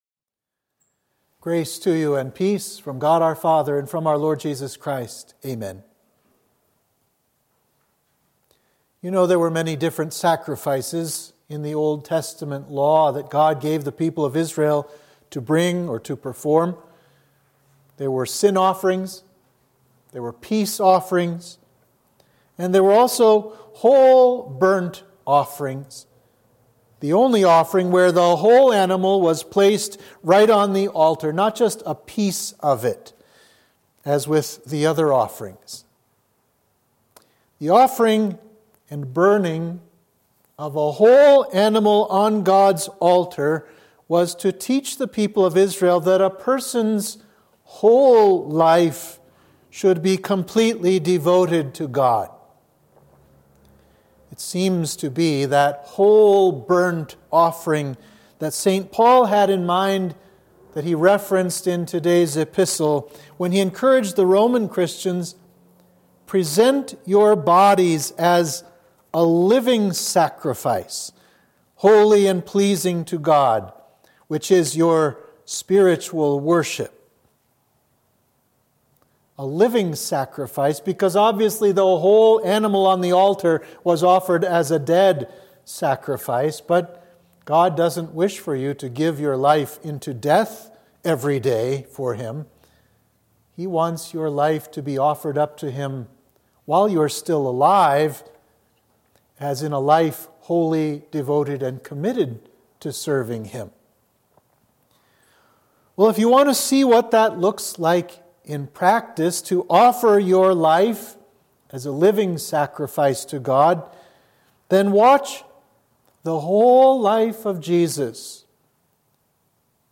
Sermon for Epiphany 1